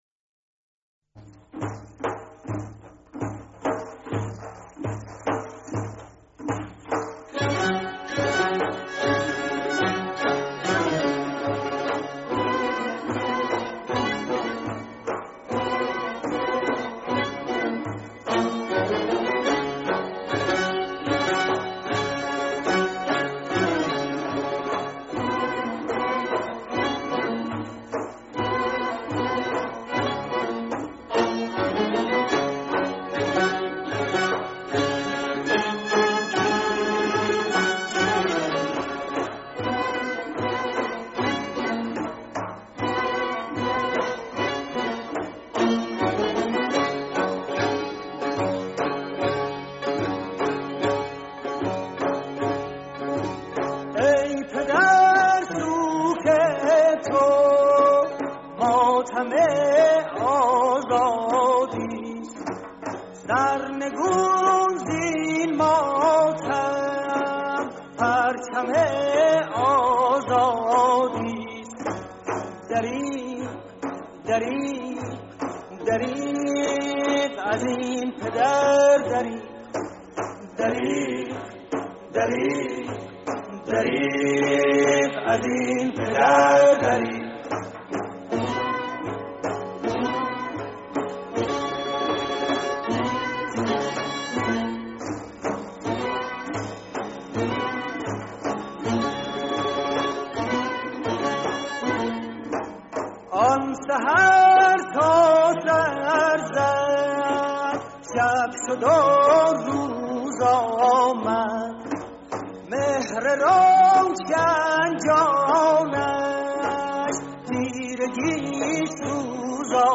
آهنگساز و نوازنده تار
تصنیف
موسیقی سنتی